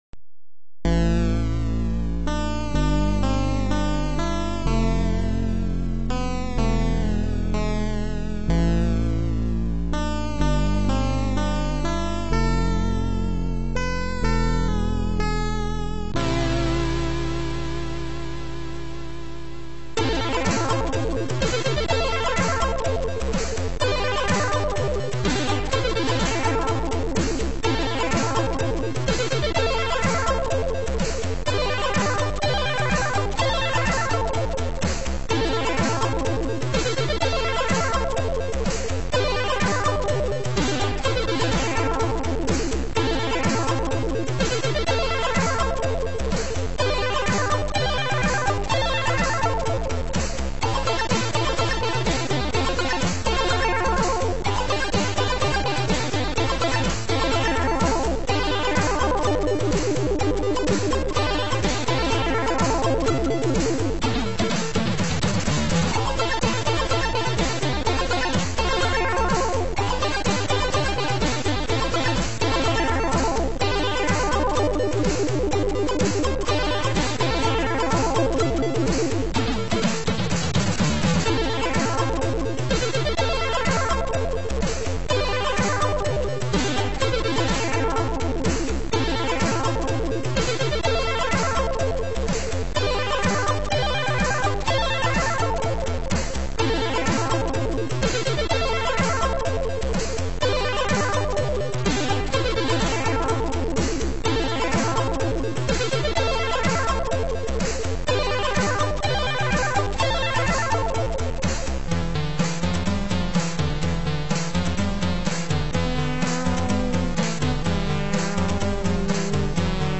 Der unverwechselbare C64-Sound kommt aus dem SID-Chip (Sound Interface Device). Hier eine gute Stunde Sound des C64:
c64-sid-sound.mp3